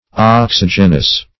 oxygenous - definition of oxygenous - synonyms, pronunciation, spelling from Free Dictionary Search Result for " oxygenous" : The Collaborative International Dictionary of English v.0.48: Oxygenous \Ox*yg"e*nous\, a. Oxygenic.
oxygenous.mp3